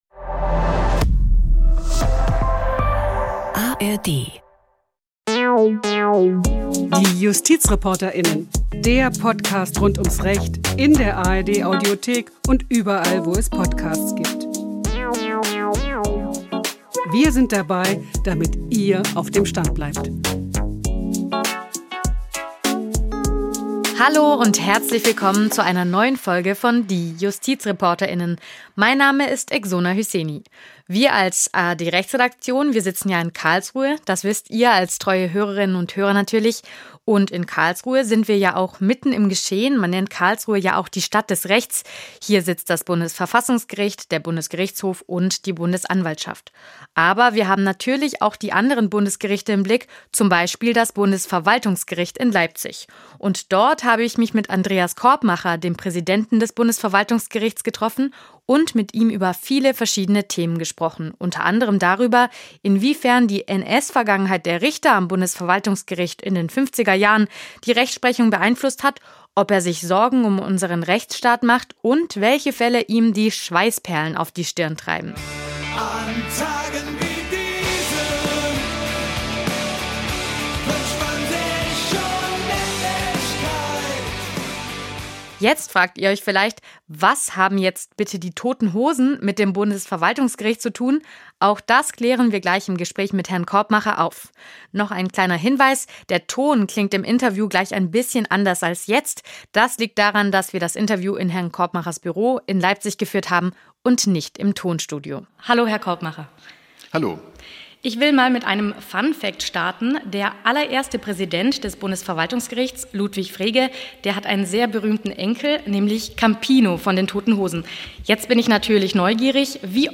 praesident-des-bundesverwaltungsgerichts-andreas-korbmacher-im-gespraech.m.mp3